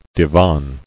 [di·van]